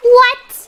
Worms speechbanks
oops.wav